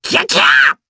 Lakrak-Vox_Attack2_jp.wav